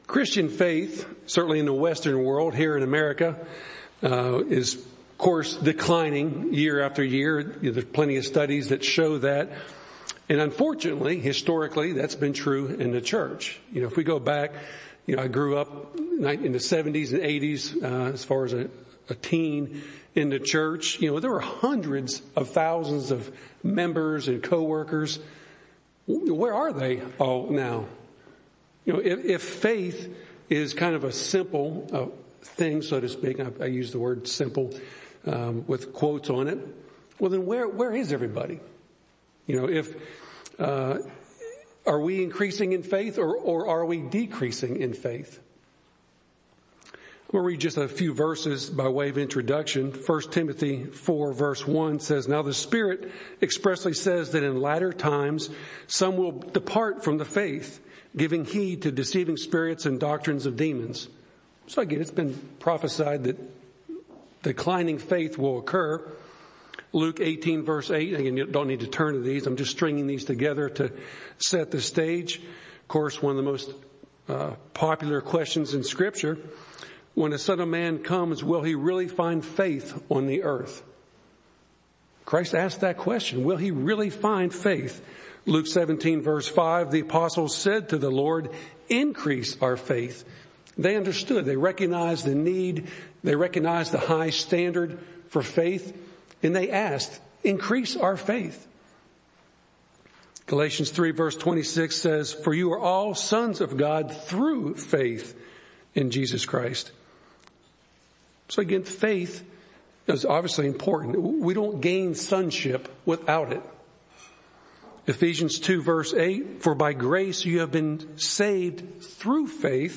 How do we grow in great, unshakeable faith? This sermon defines faith from the Bible so we can understand it better and grow in it.
Given in Nashville, TN